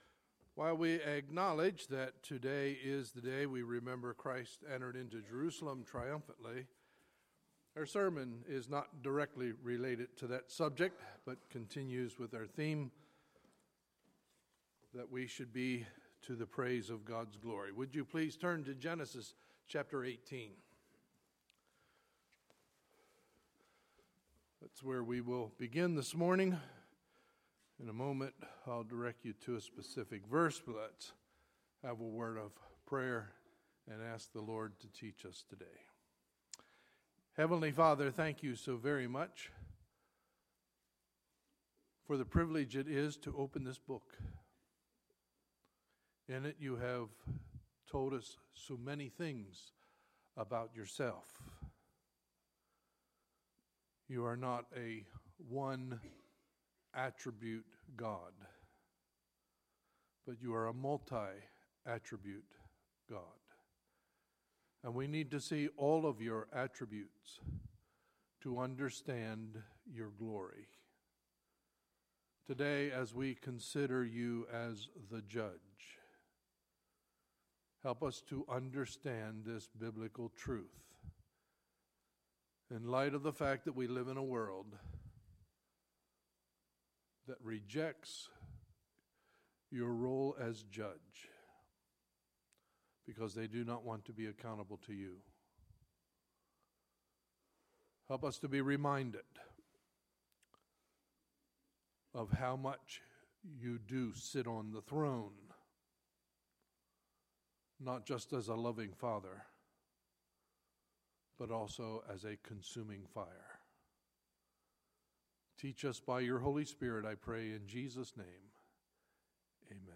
Sunday, March 20, 2016 – Sunday Morning Service